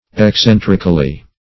Eccentrically \Ec*cen"tric*al*ly\, adv.